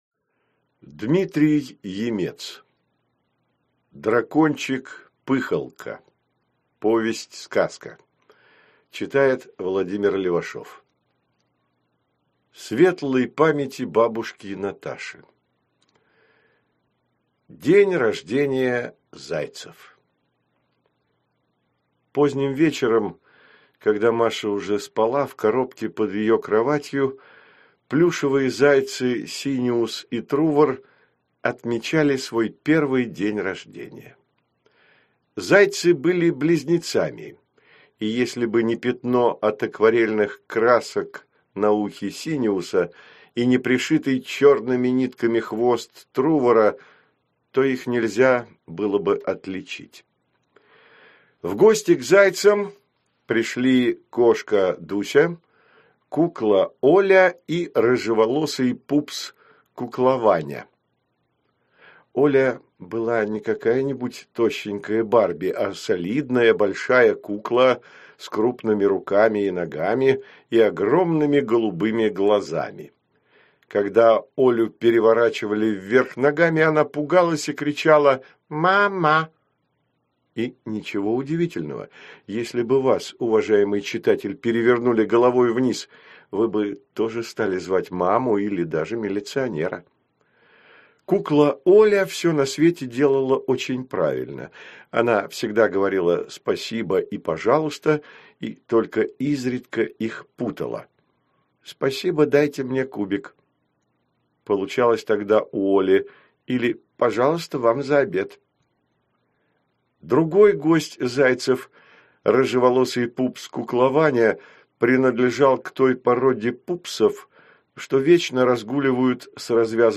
Аудиокнига Дракончик Пыхалка | Библиотека аудиокниг